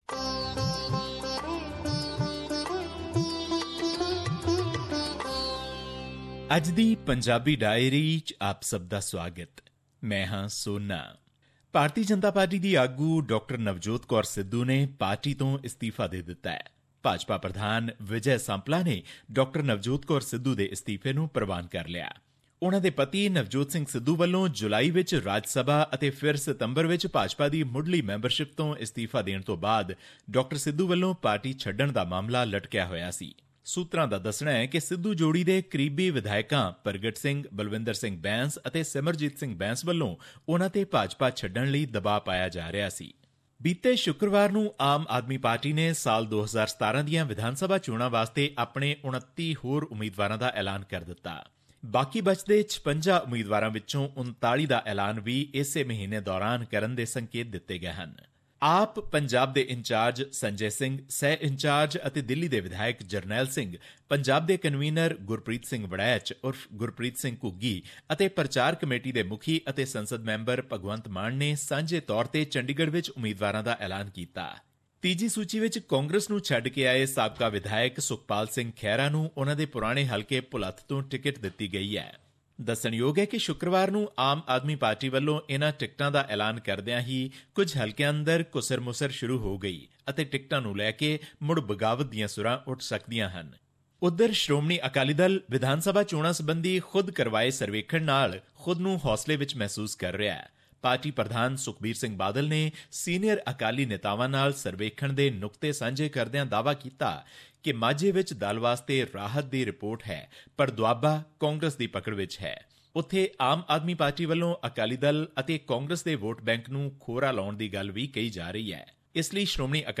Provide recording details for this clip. His report was presented on SBS Punjabi program on Monday, Oct 10, 2016, which touched upon issues of Punjabi and national significance in India. Here's the podcast in case you missed hearing it on the radio.